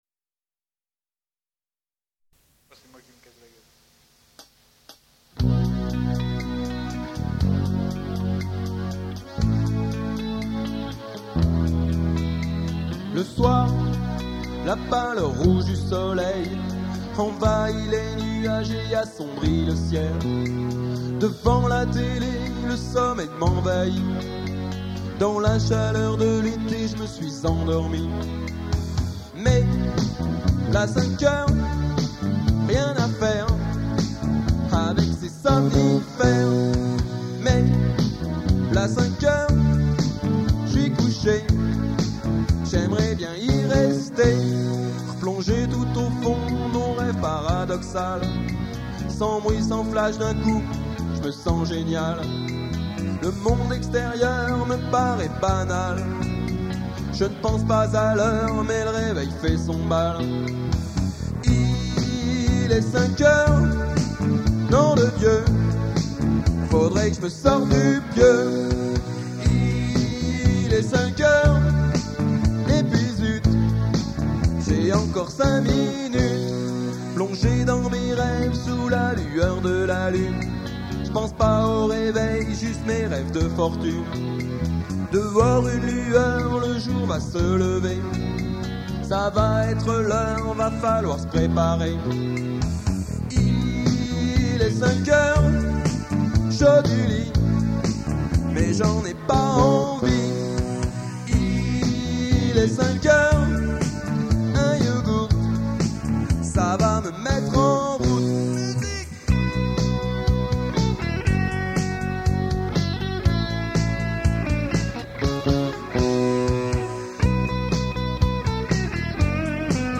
premier enregistrement, première K7